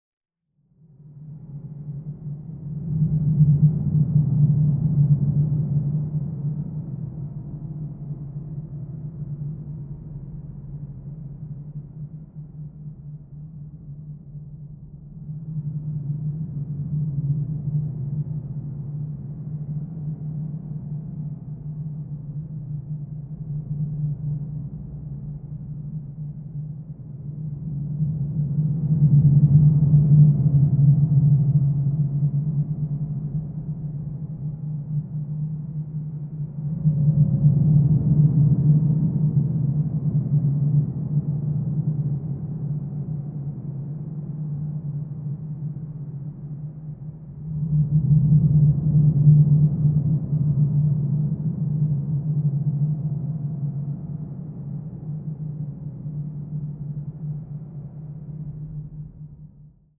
Ощутите загадочную атмосферу пещер с нашей коллекцией натуральных звуков.
Шепот ветра в пещере